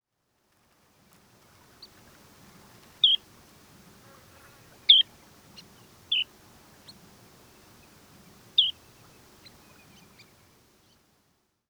ヒバリシギ
【分類】 チドリ目 シギ科 オバシギ属 ヒバリシギ 【分布】北海道(旅鳥)、本州(旅鳥、冬鳥)、四国(旅鳥)、九州(旅鳥、一部冬鳥)、沖縄(冬鳥) 【生息環境】 農耕地、湿地に生息 【全長】14.5cm 【主な食べ物】昆虫 【鳴き声】地鳴き 【聞きなし】「プルル」